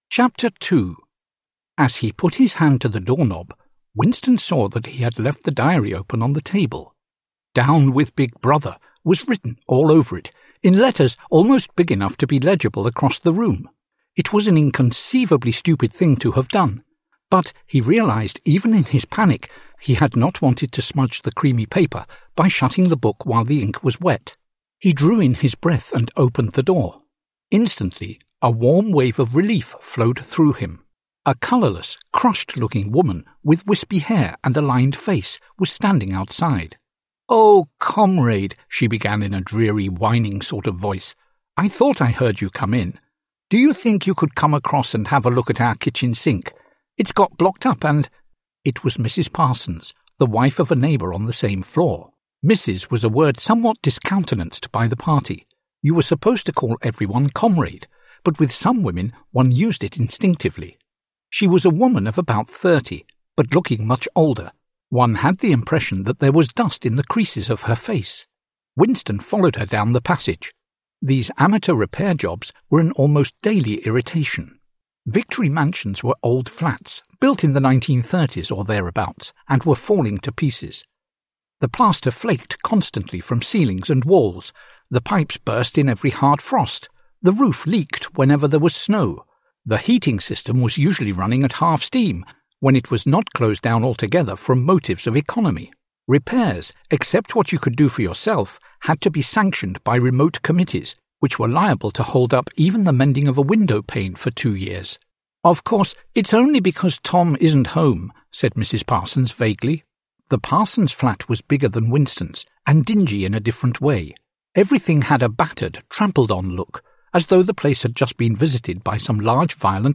AUDIOBOOK (mp3) Rok 1984 w wersji do nauki angielskiego